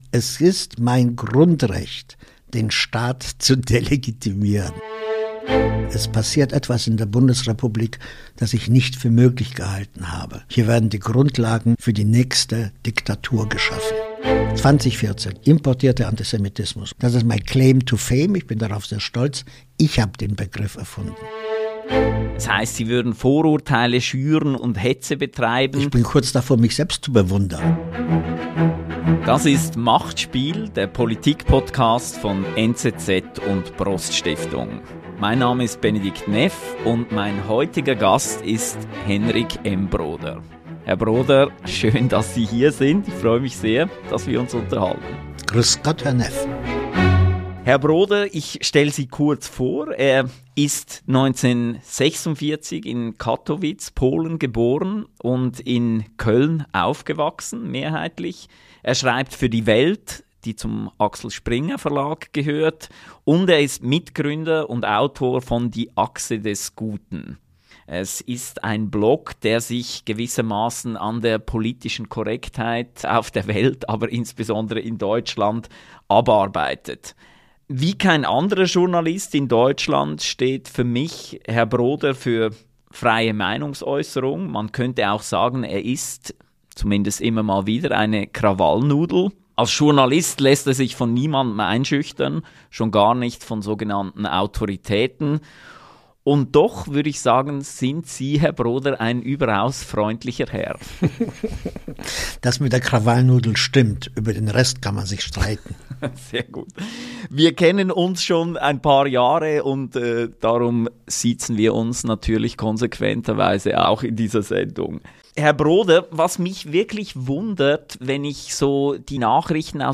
Heutiger Gast: Henryk M. Broder, Autor